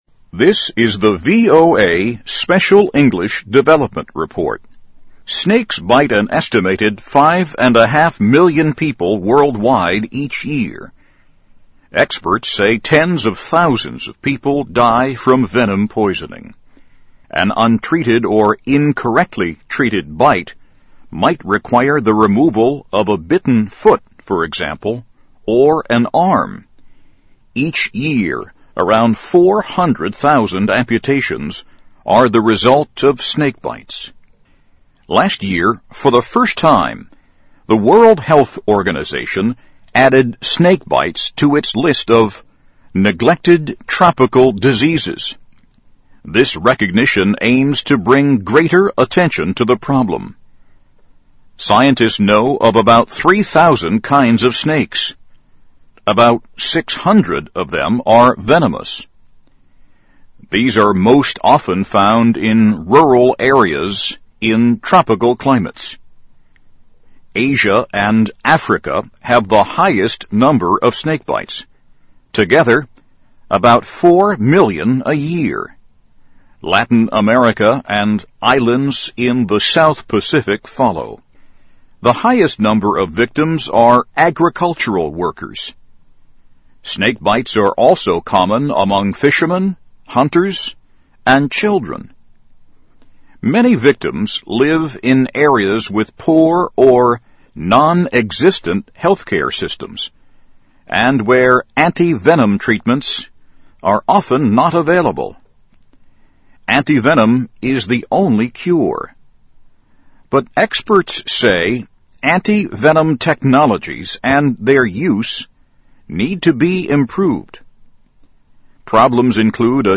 VOA慢速英语2010年-Development Report - Steps Urged to Pr 听力文件下载—在线英语听力室